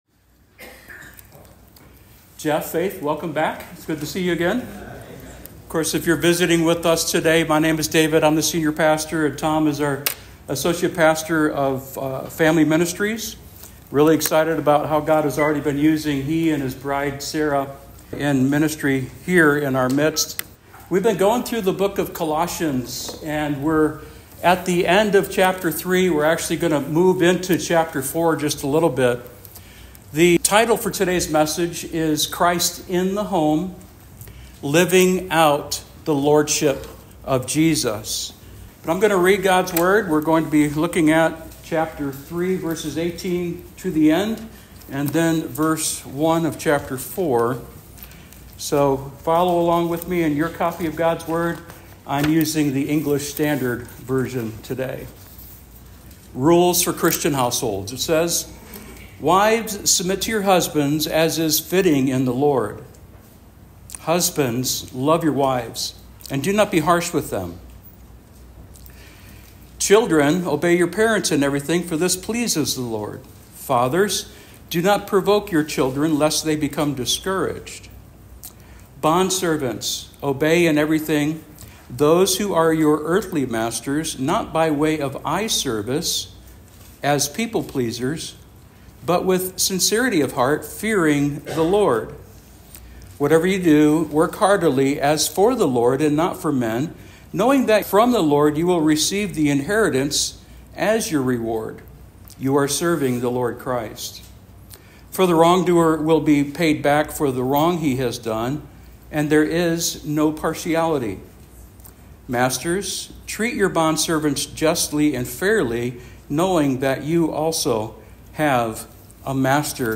SERMON OUTLINE : Wives submit (18) Husbands love (19) Children obey (20) Fathers encourage (21) … note: some translations say “parents”.